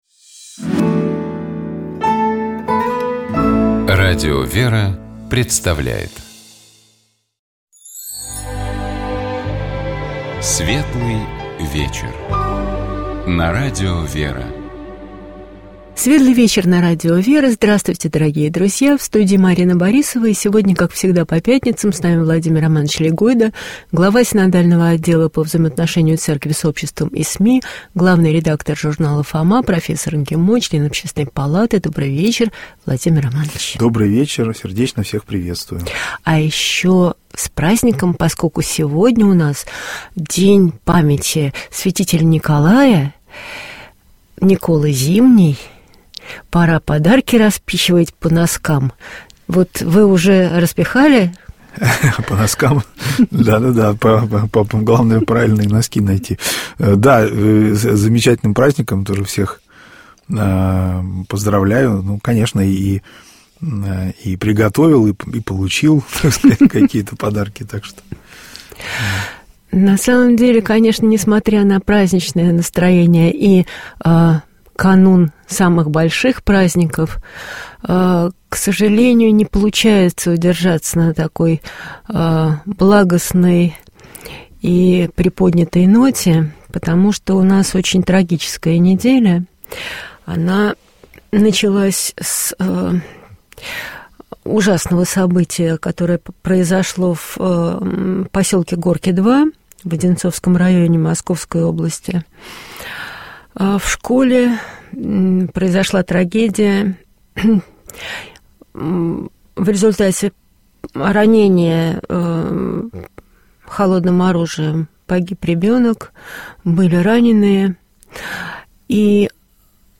Гость программы — Владимир Легойда, председатель Синодального отдела по взаимоотношениям Церкви с обществом и СМИ, член Общественной палаты РФ.